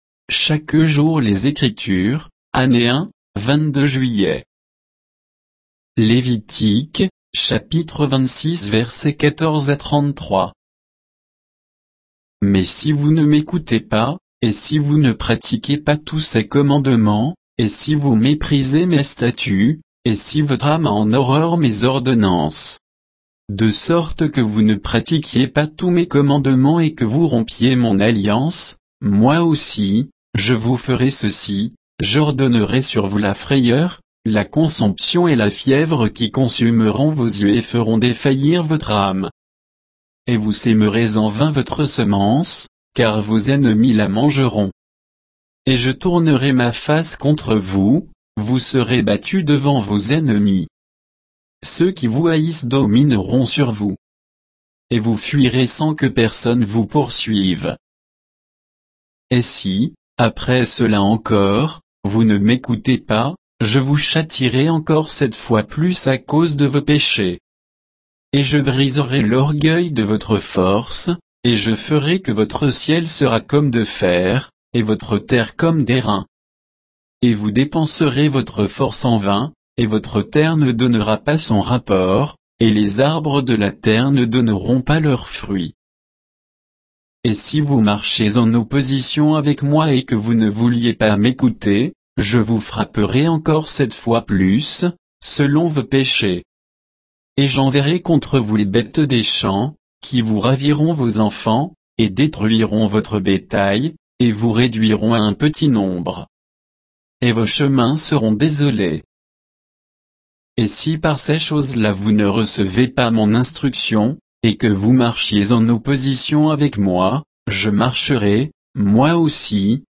Méditation quoditienne de Chaque jour les Écritures sur Lévitique 26